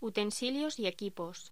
Locución: Utensilios y equipos
voz